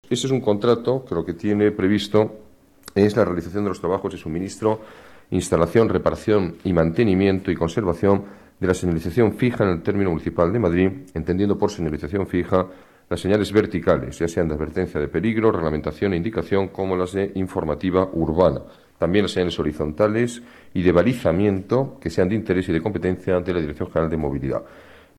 Nueva ventana:Declaraciones alcalde, Alberto Ruiz-Gallardón: señalización fija